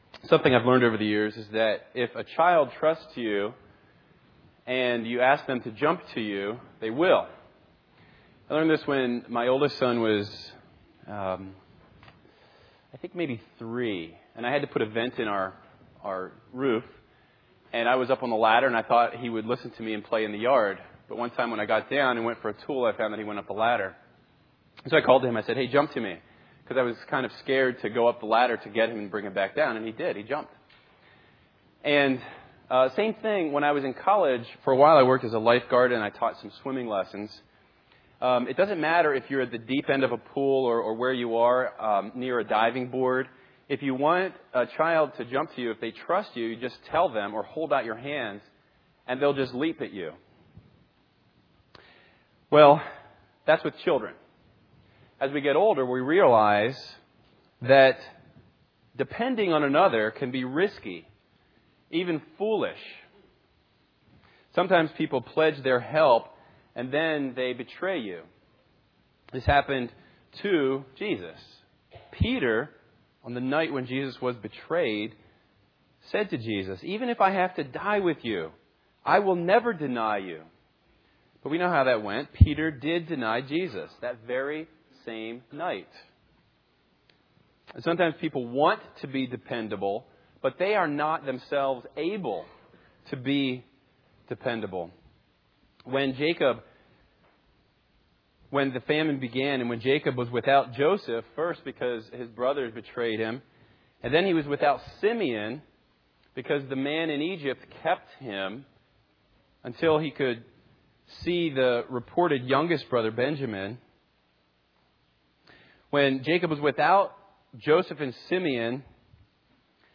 A Collection of 2016 Sermons